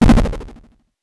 8 bits Elements